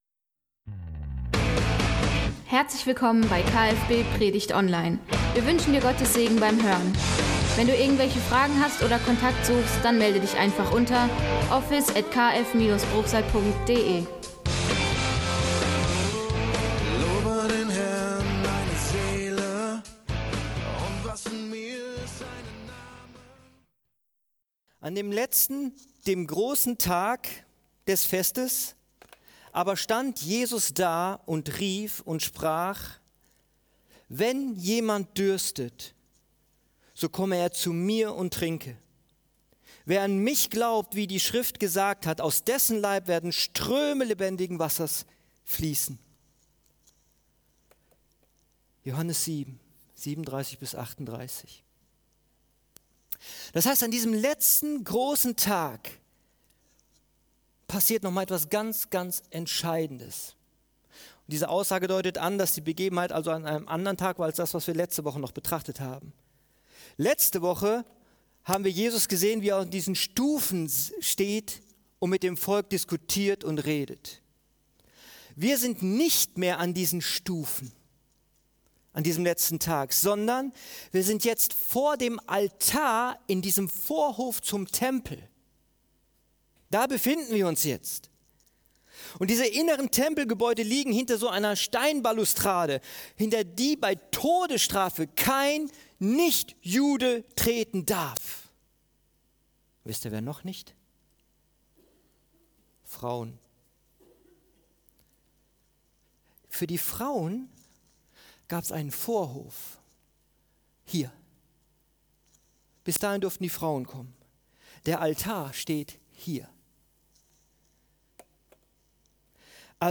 Verdurstende Seelen – Kirche für Bruchsal